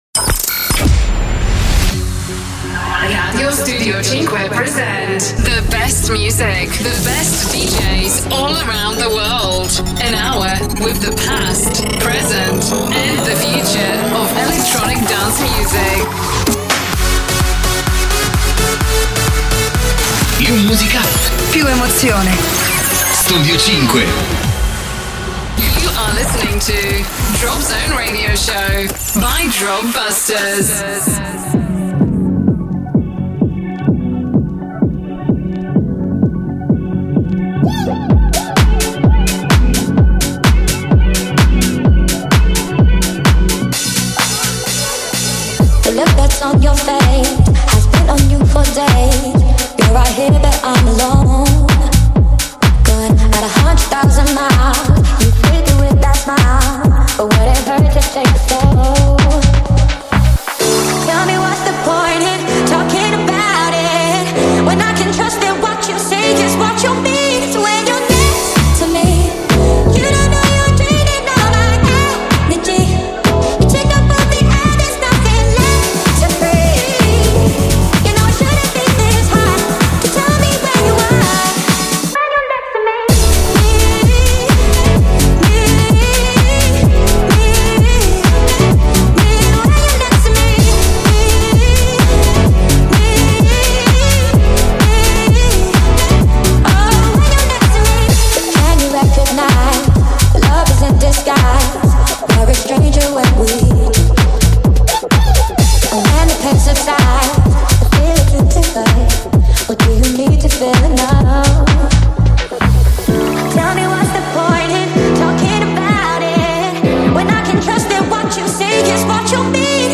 Radioshow